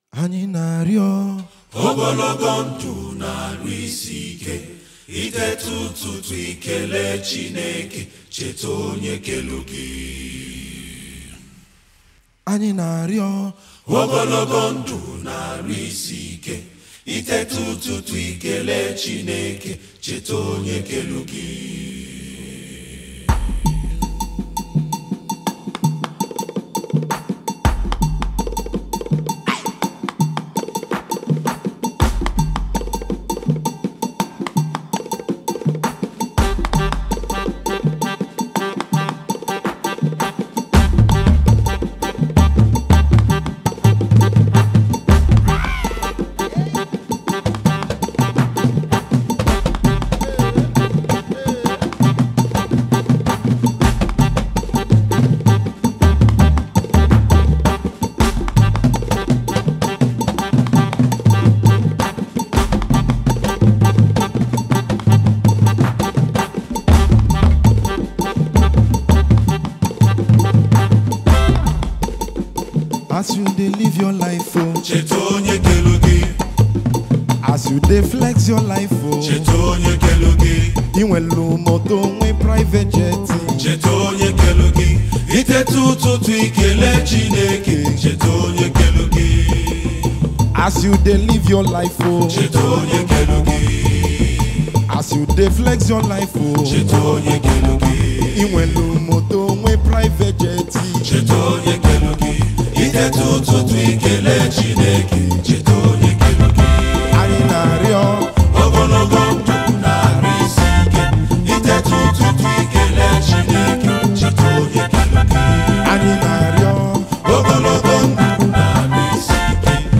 February 8, 2025 Publisher 01 Gospel 0
songwriter and saxophonist.
music comes heavily blended in traditional African rhythms